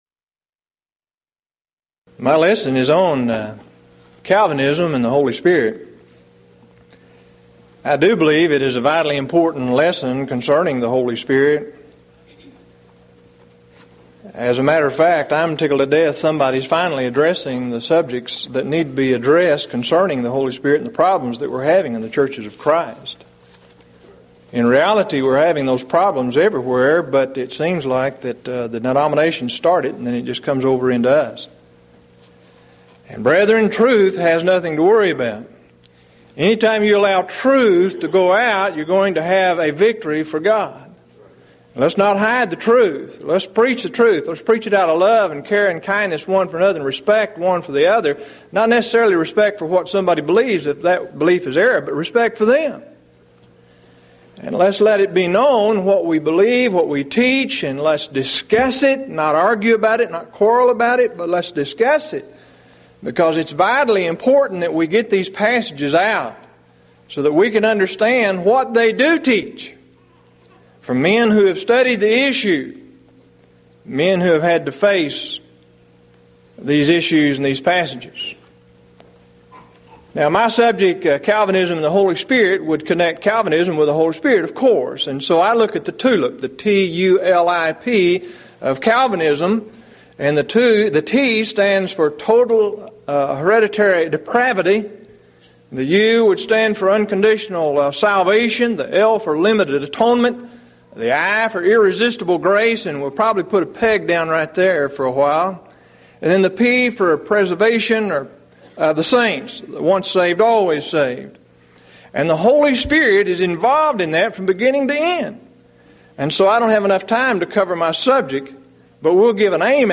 Event: 1997 Mid-West Lectures